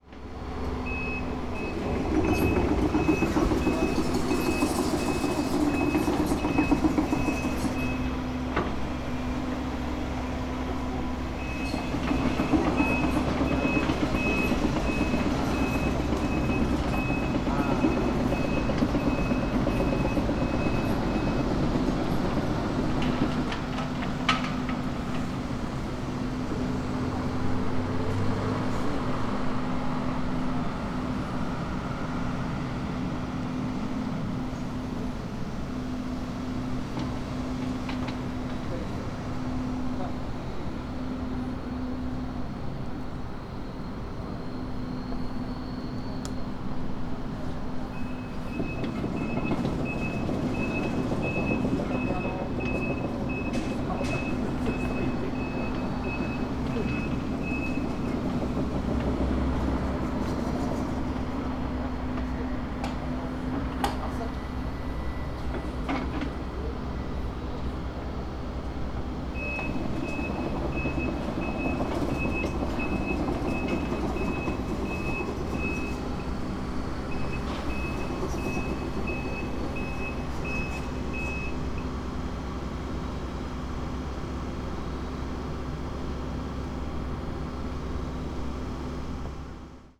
Fukushima Soundscape: Mt. Shinobu